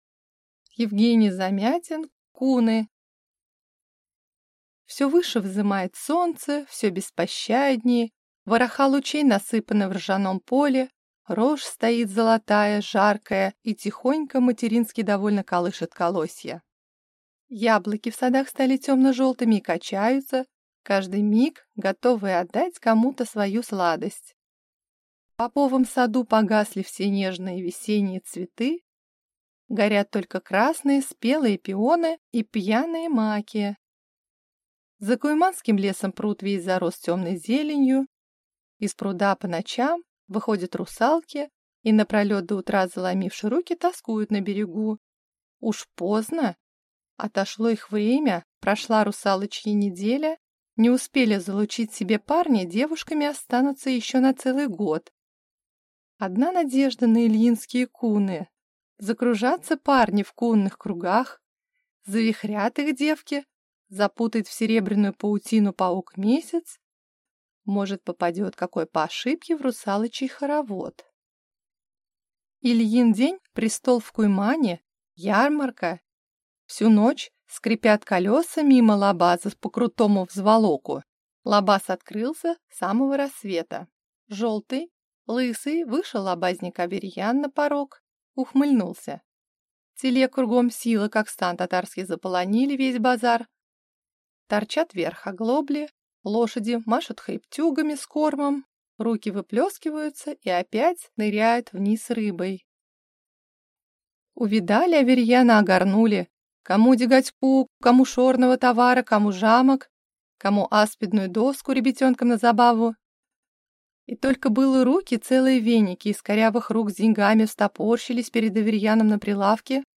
Аудиокнига Куны | Библиотека аудиокниг